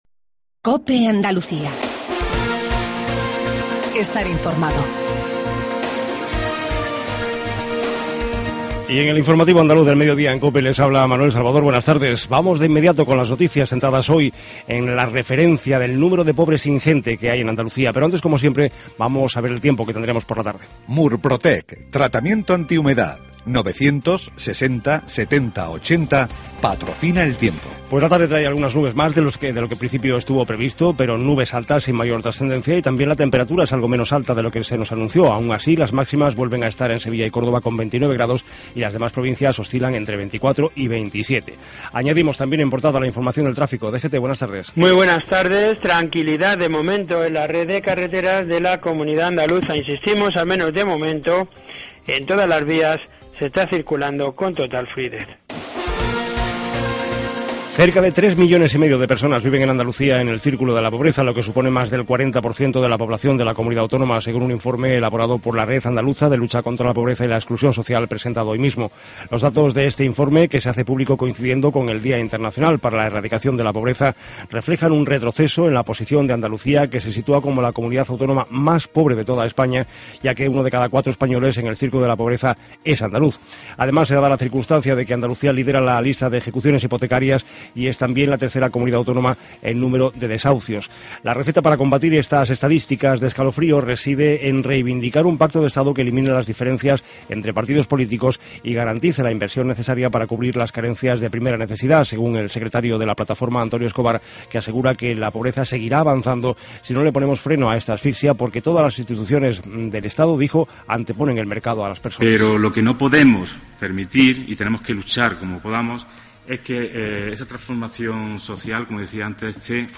INFORMATIVO REGIONAL MEDIODIA COPE ANDALUCIA